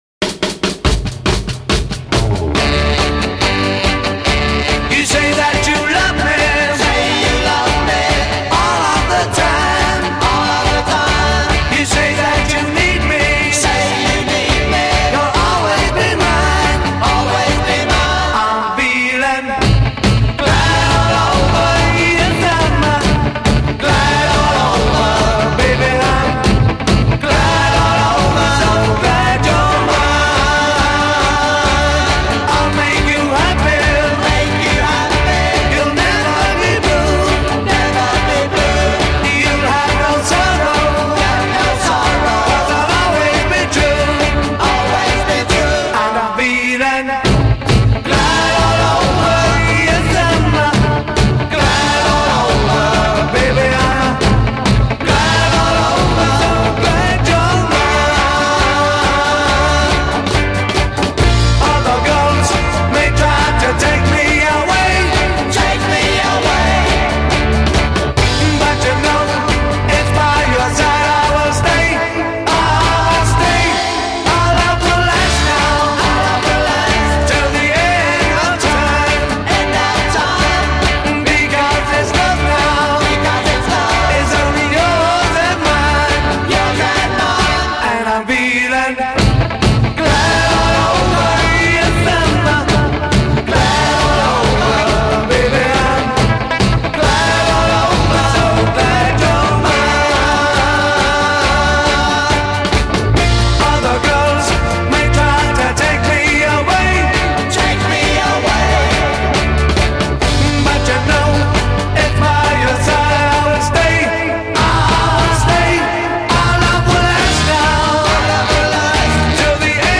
organ and vocals
guitar and vocals
drums and vocals
bass guitar and vocals
tenor saxophone
Intro 0:00   double-tracked (?) drum intro with vamping band
A verse 0:   double-tracked soloist and responding chorus a
refrain :   soloist with chorus joining at line ends b
B chorus :   vocal solo with responding chorus d
B chorus :   as in chorus above with modulation bridge d
outro :   repeat last line of refrain. b